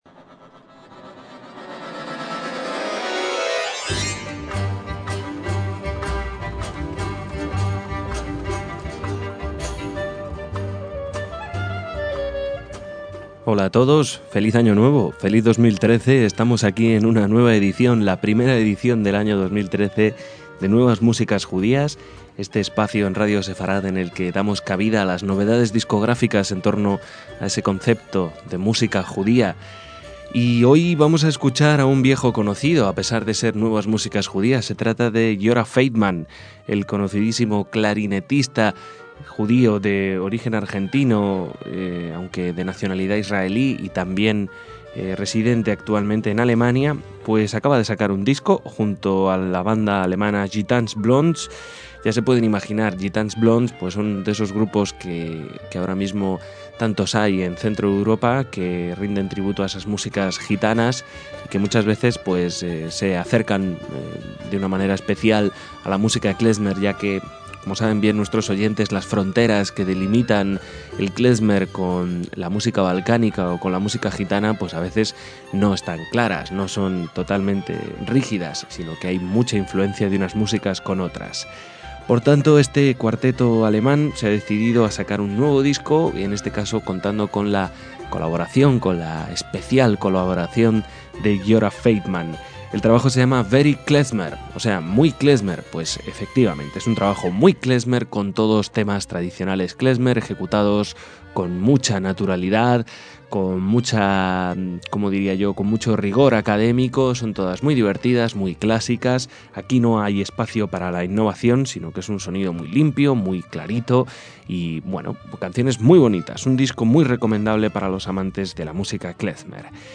clarinetista
violinista
acordeón
guitarra
contrabajo